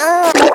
NRG Vocals-1.wav